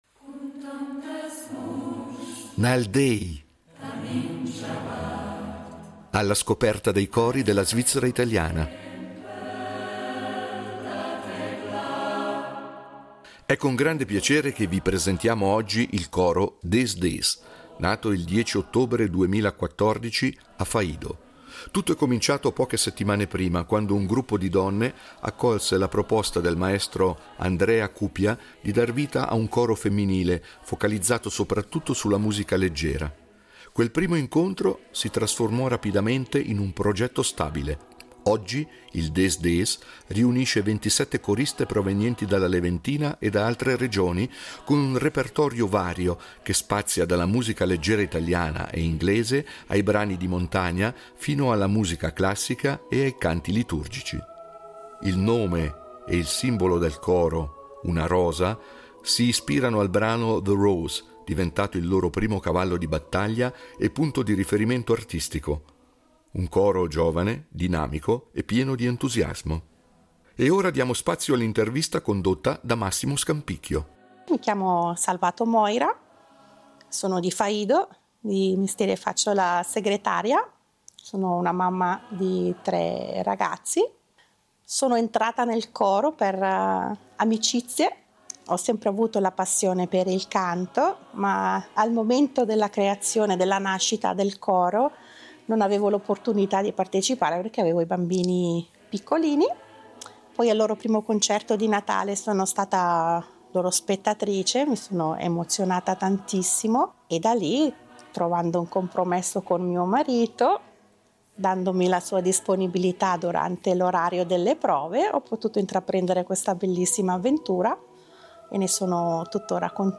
Nal déi, cori della svizzera italiana
è un coro femminile di 27 elementi provenienti dalla Leventina e altre regioni, con un repertorio che spazia dalla musica leggera a quella classica e liturgica